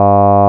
Siin kujutatud vokaalid on sünteesitud Eek & Meister (1998) andmete põhjal, kus /a/ F1 = 670 Hz, F2 = 1070 Hz ja F3 = 2460 Hz ning /e/ F1 = 435 Hz, F2 = 2010 Hz ja F3 = 2545 Hz.
synt_vok_a.wav